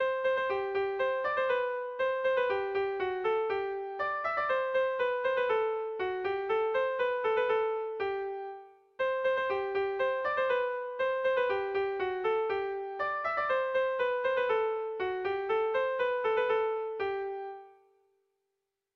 Erromantzea
ABDE